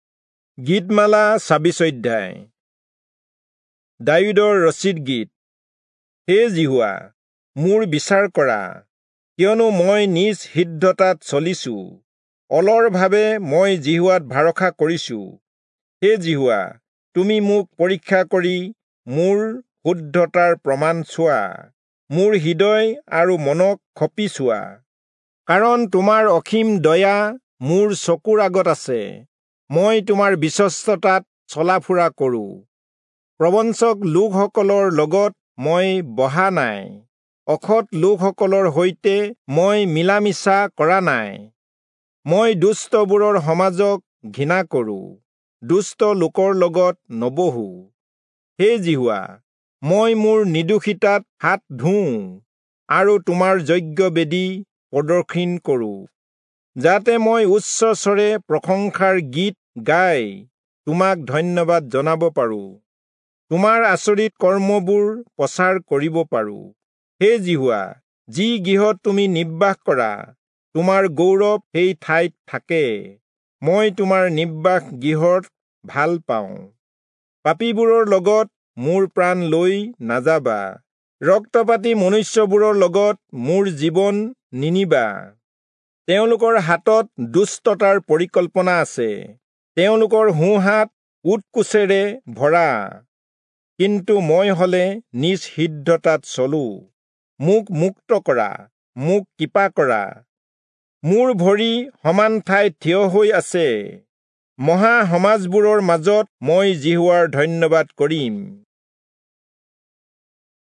Assamese Audio Bible - Psalms 145 in Irvml bible version